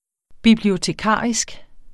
Udtale [ biblioteˈkɑˀisg ]